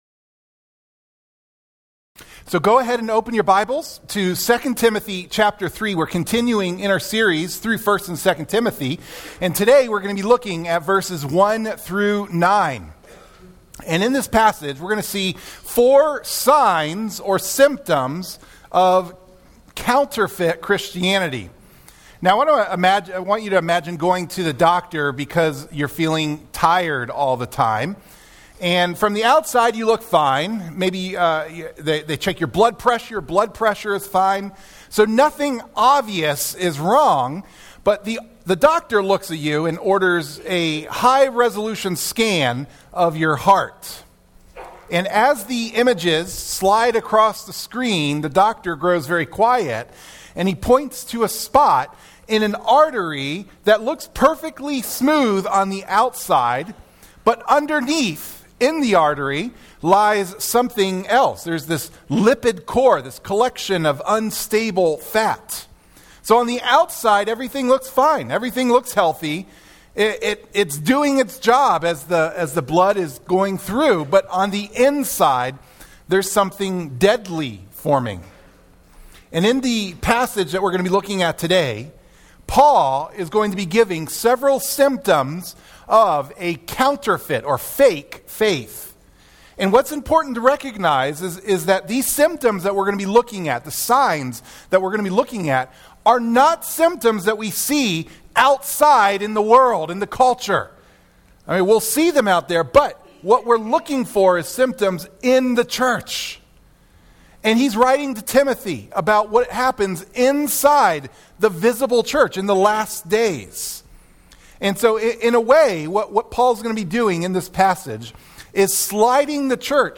Sermon Clip The message notes for the sermon can be downloaded by clicking on the “save” button.